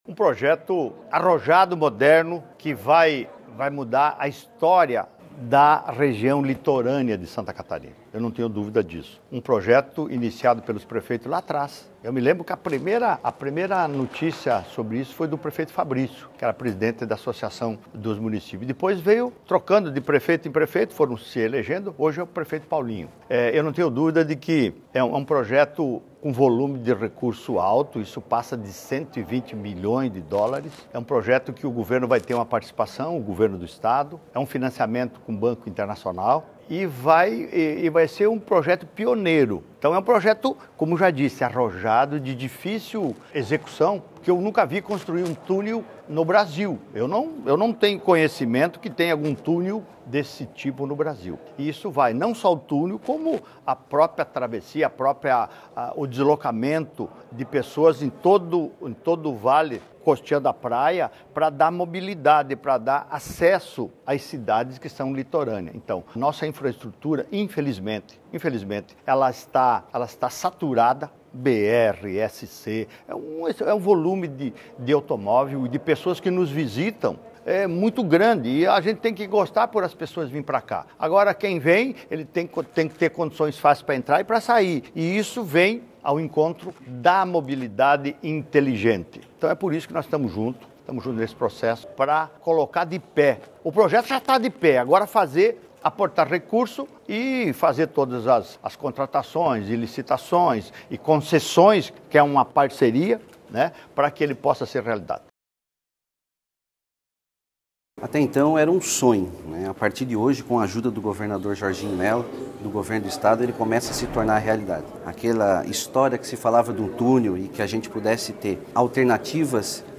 O governador Jorginho Mello destaca que o projeto visa melhorar a mobilidade em uma das regiões mais movimentadas do estado, especialmente durante a temporada de verão:
O presidente da Amfri e prefeito de Bombinhas, Paulinho Muller, define como fundamental o apoio recebido do Governo do Estado para a execução do projeto:
SECOM-Sonoras-Projeto-Mobilidade-Amfri.mp3